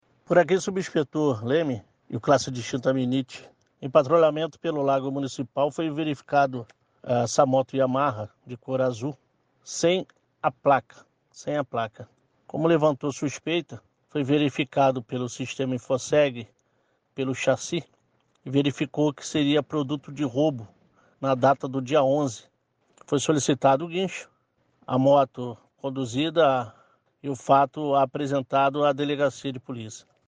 comentou sobre a ocorrência, ouça: